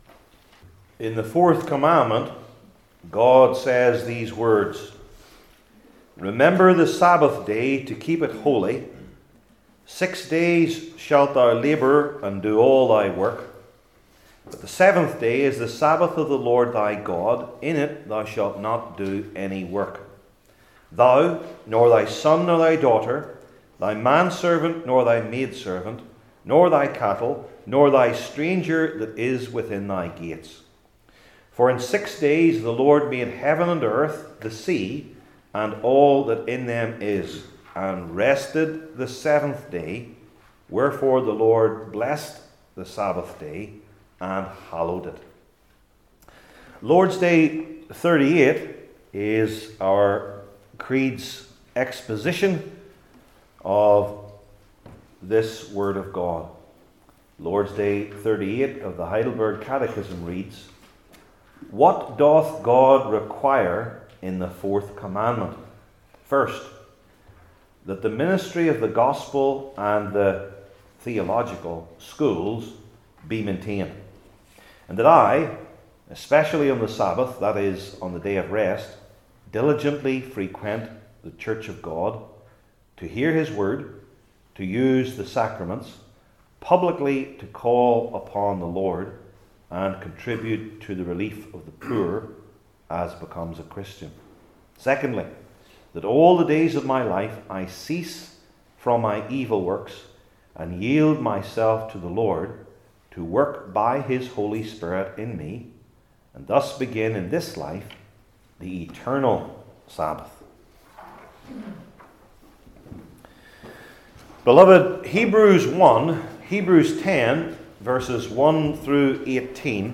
Hebrews 10:1-31 Service Type: Heidelberg Catechism Sermons I. The Meaning II.